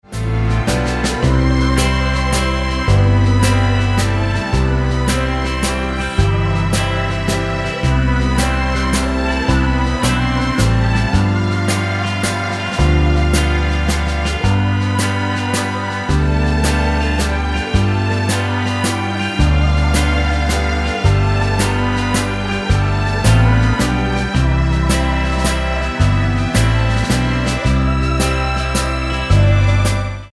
Tonart:C mit Chor